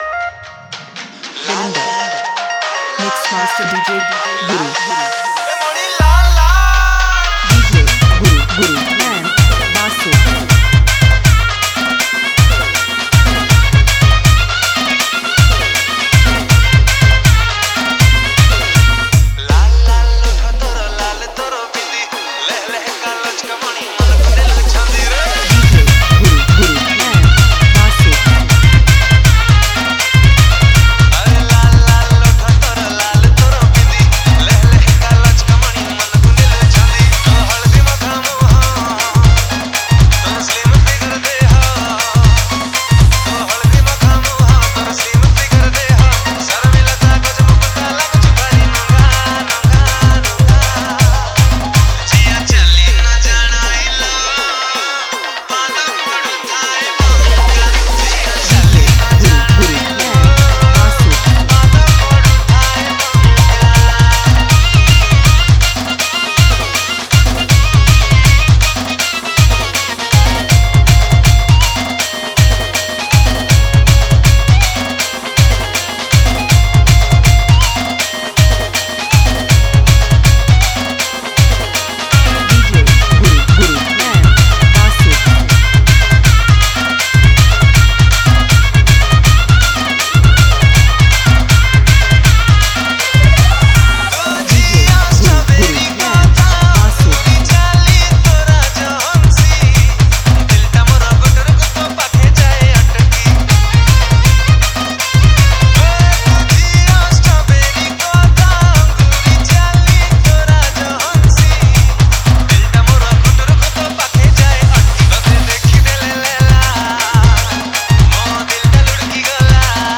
Category: Odia Gaana Sambalpuri Viral Cg Dj Mix Song 2021